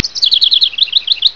snd_17683_bird.wav